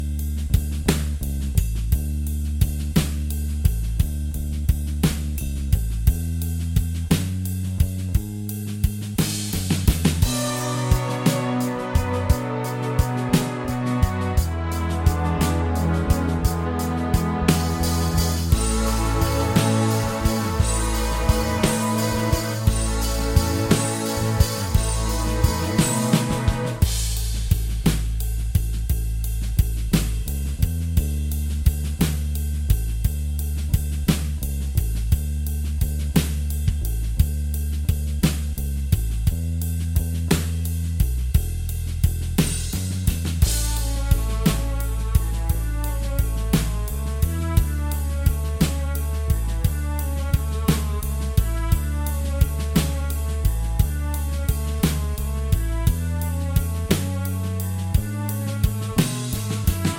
Minus Main Guitars For Guitarists 3:53 Buy £1.50